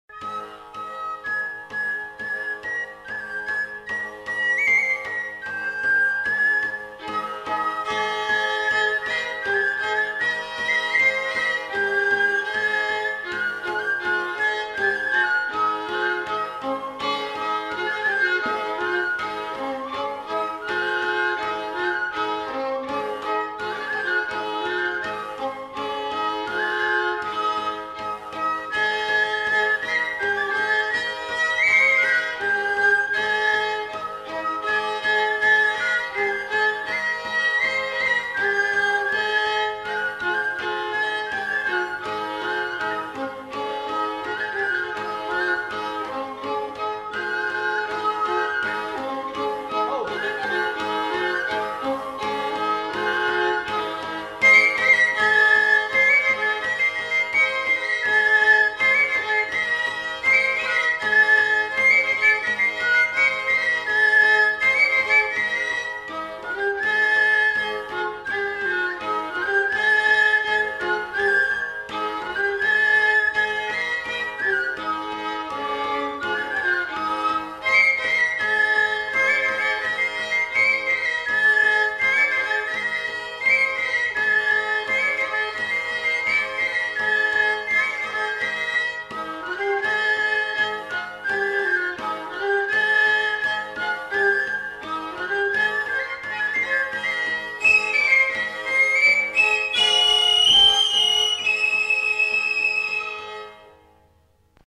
Aire culturelle : Béarn
Lieu : Bielle
Genre : morceau instrumental
Instrument de musique : violon ; flûte à trois trous ; tambourin à cordes
Danse : branlo airejan